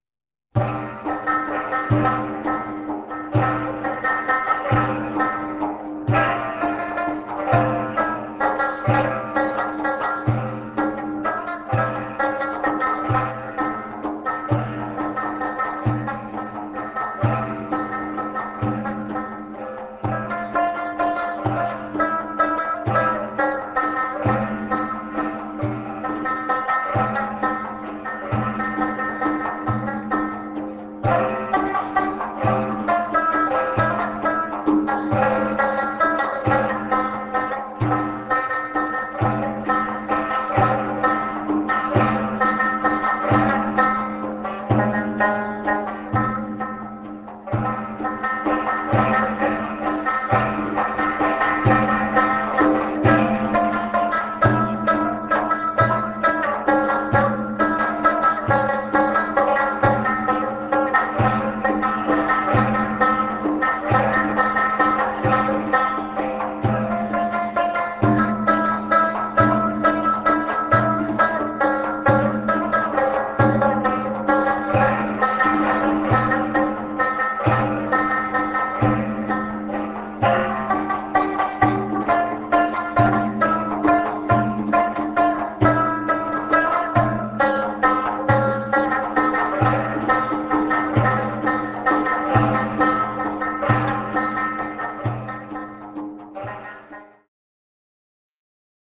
deff, zarb, tar, dumbek